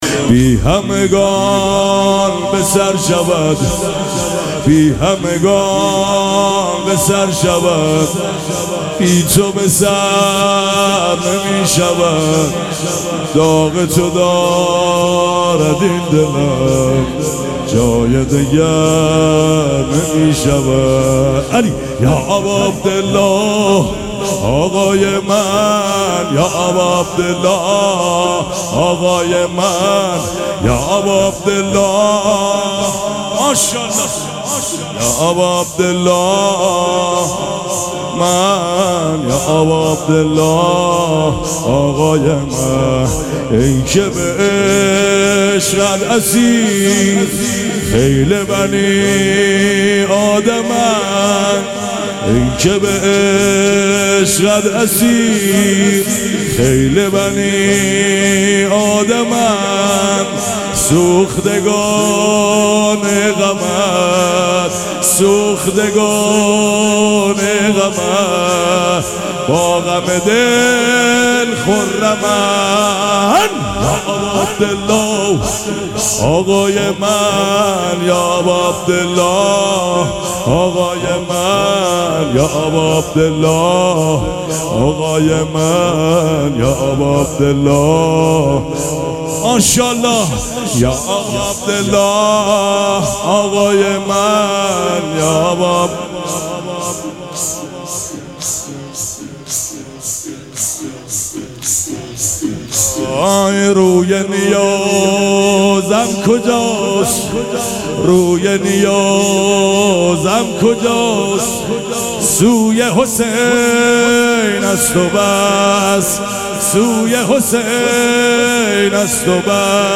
شب اول مراسم عزاداری اربعین حسینی ۱۴۴۷
شور
مداح
مراسم عزاداری اربعین حسینی